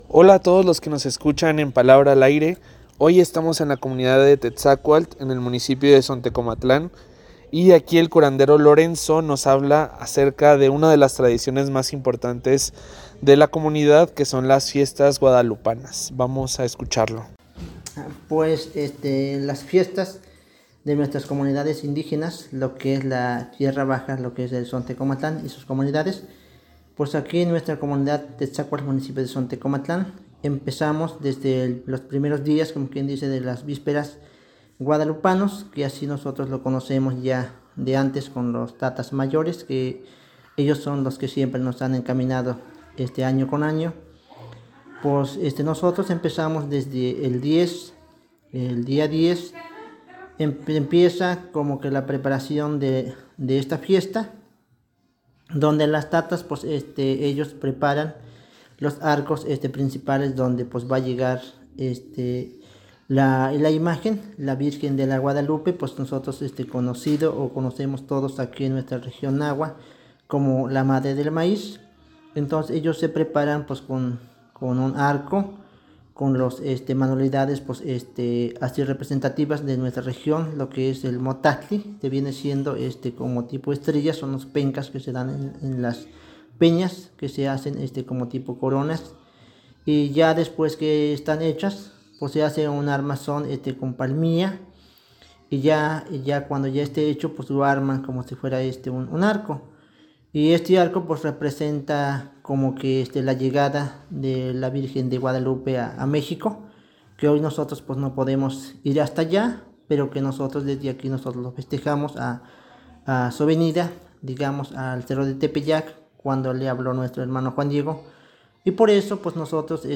Desde Tetzacual en Zontecomatlán compartimos la palabra.